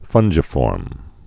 (fŭnjə-fôrm, fŭnggə-)